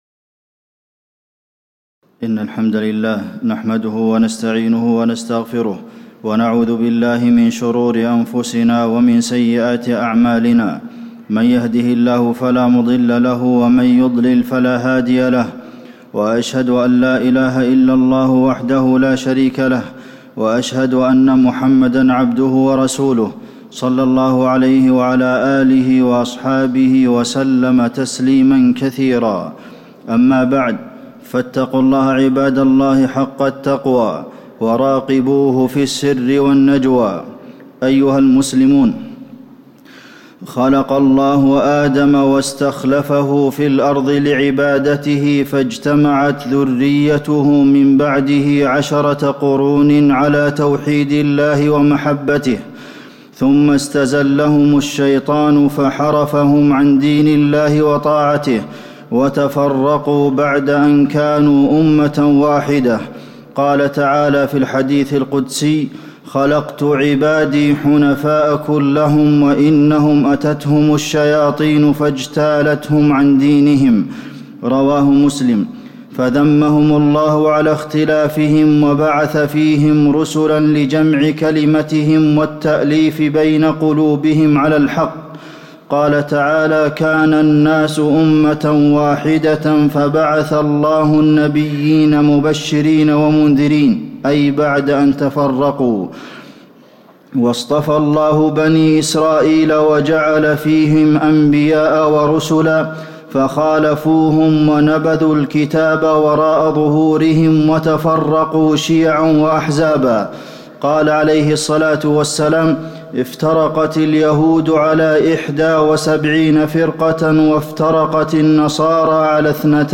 تاريخ النشر ١١ صفر ١٤٣٨ هـ المكان: المسجد النبوي الشيخ: فضيلة الشيخ د. عبدالمحسن بن محمد القاسم فضيلة الشيخ د. عبدالمحسن بن محمد القاسم ذم الإختلاف والفرقة